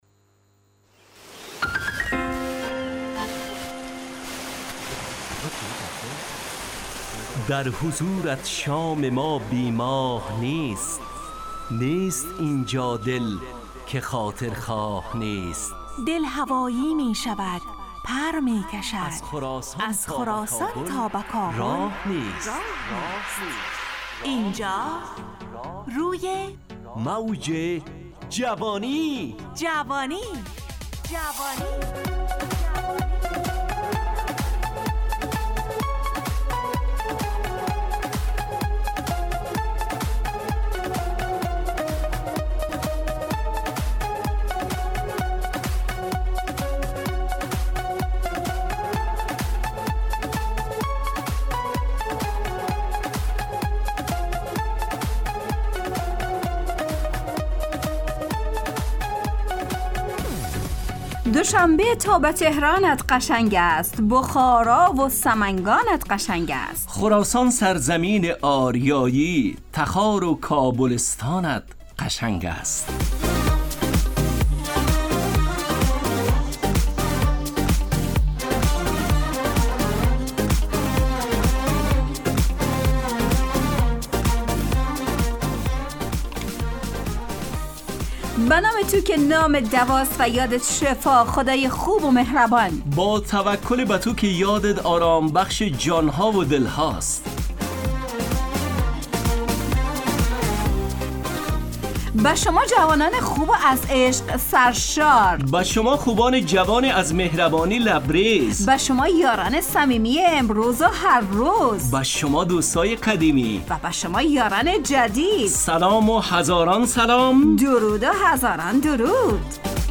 همراه با ترانه و موسیقی مدت برنامه 70 دقیقه . بحث محوری این هفته (سلامتی)